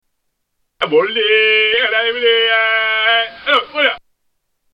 Mummbling